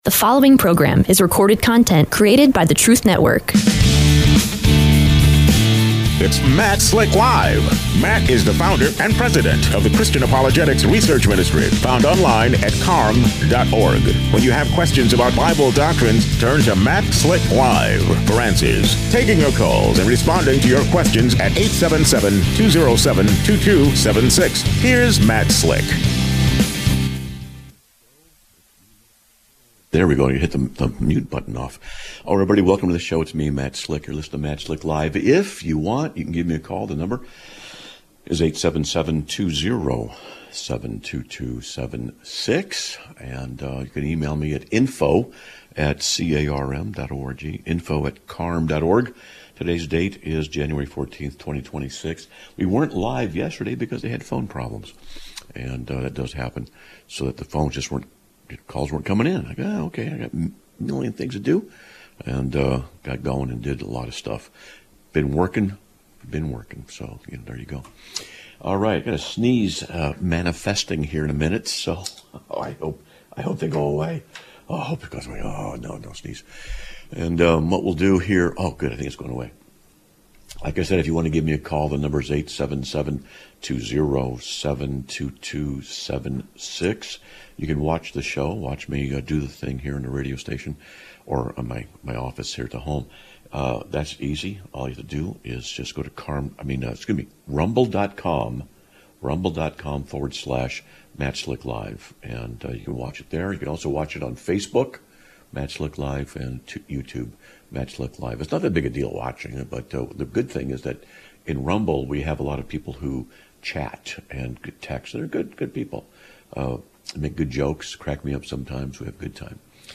Live Broadcast of 01/14/2026